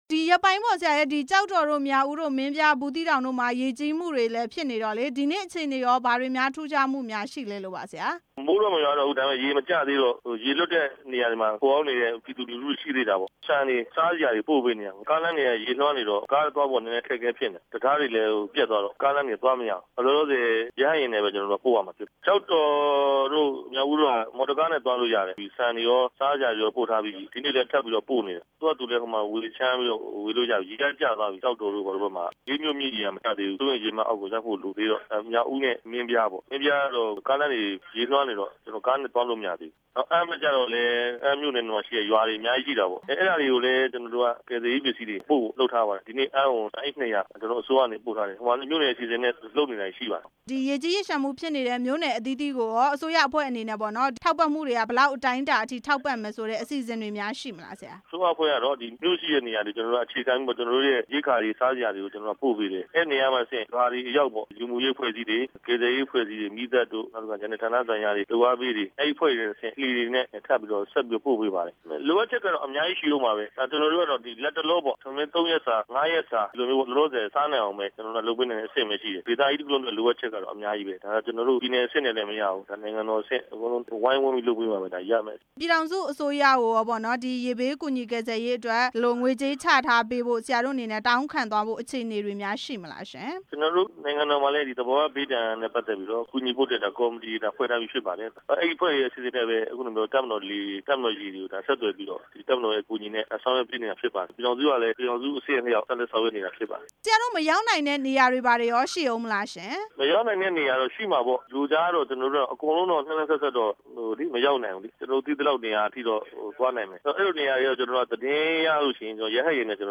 ရခိုင်ပြည်နယ်က ရေဘေးကယ်ဆယ်ရေး ဆောင်ရွက်နေမှု မေးမြန်းချက်